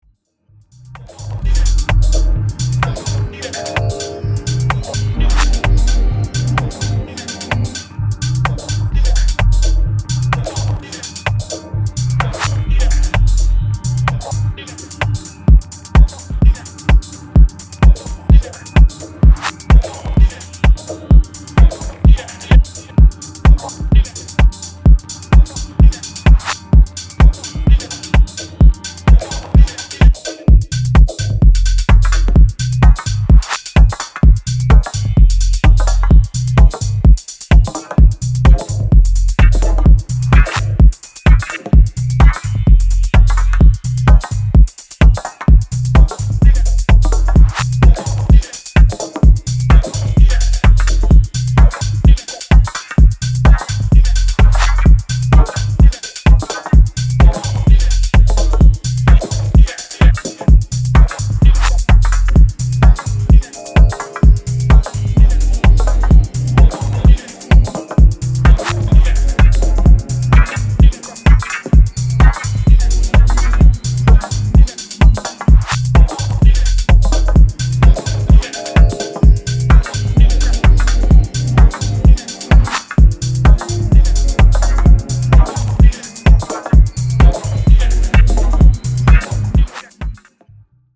Minimalistic Vibes with low-lit house embellishments.